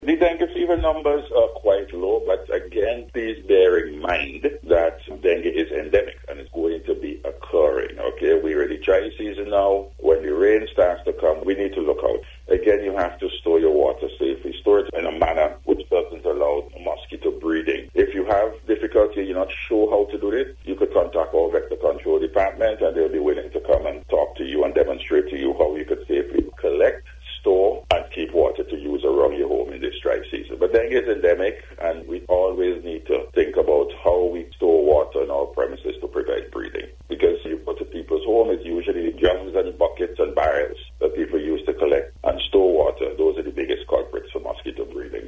He made this statement during an interview with NBC News as he noted that Dengue Fever is endemic to St. Vincent and the Grenadines and there will be flare-ups from time to time.